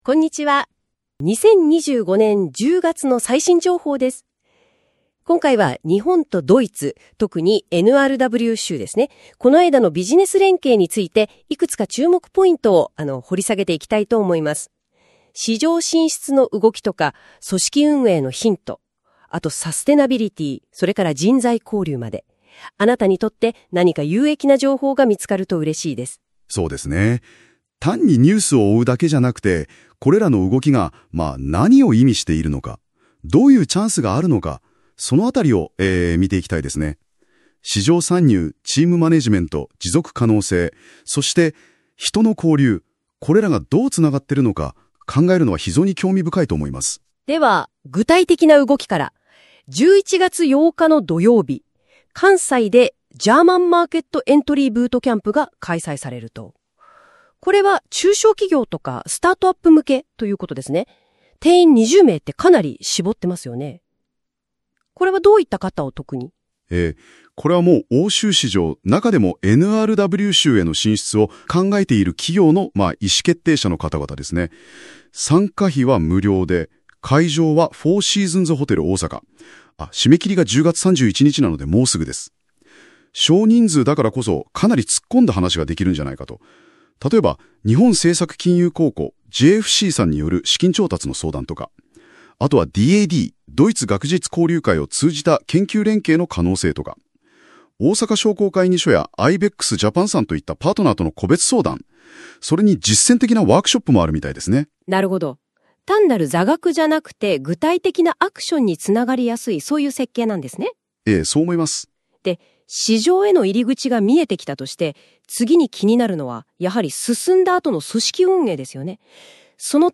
ラジオDJ風の2人の掛け合い。音声で楽しむPodcast